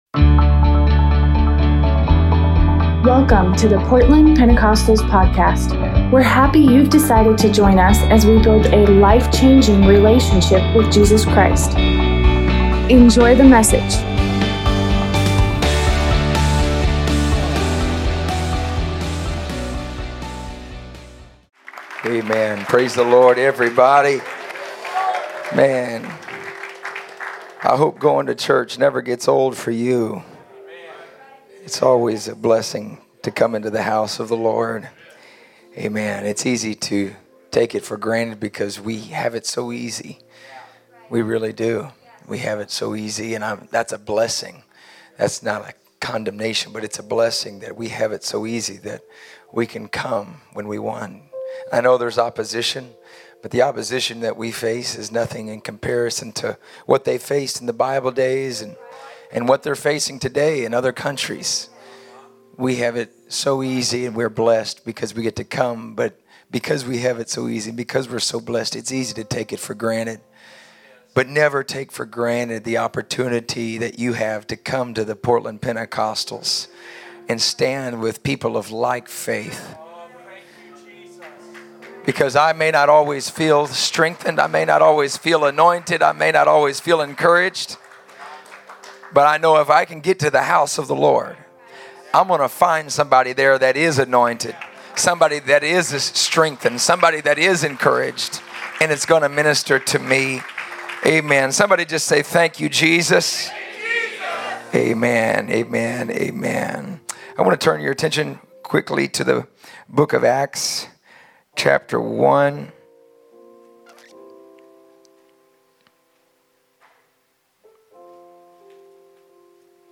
Tuesday night revival service